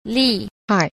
6. 厲害 – lìhài – lệ hại (ghê gớm)